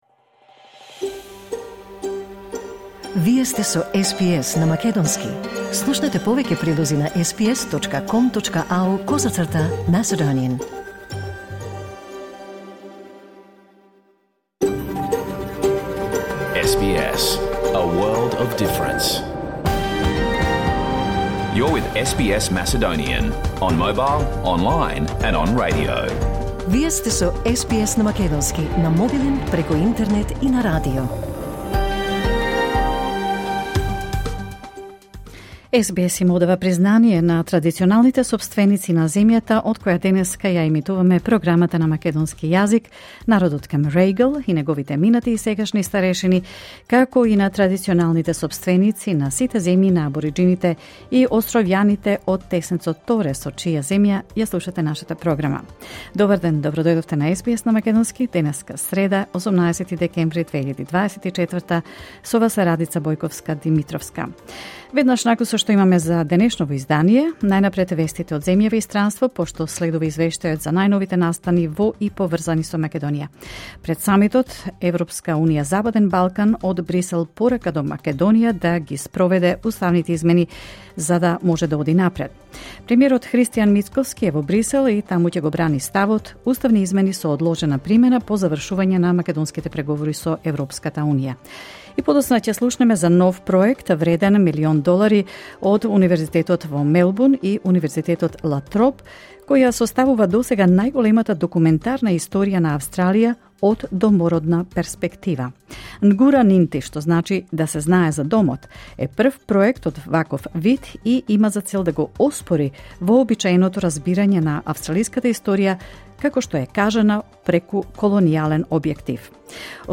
SBS Macedonian Program Live on Air 18 December 2024